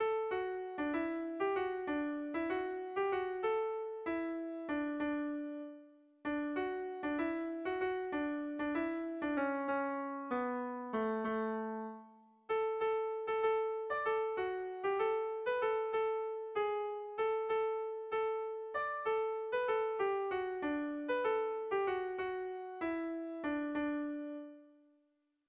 Zortziko txikia (hg) / Lau puntuko txikia (ip)
ABDE